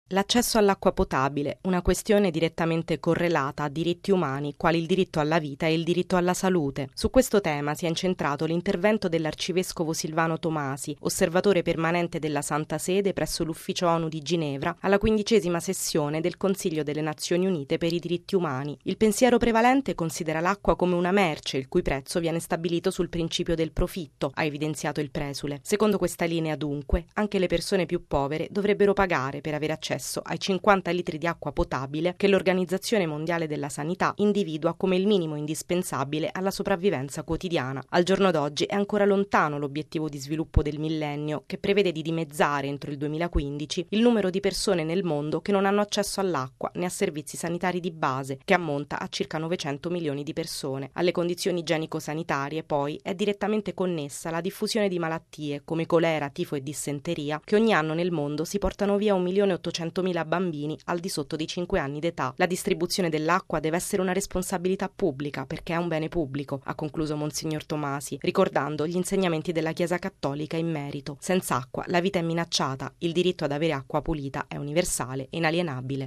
Questo è l’insegnamento della Chiesa cattolica, ribadito ieri dall’arcivescovo Silvano Maria Tomasi, osservatore permanente della Santa Sede presso l’ufficio Onu di Ginevra, intervenuto ieri alla 15.ma sessione del Consiglio delle Nazioni Unite per i Diritti umani. Il servizio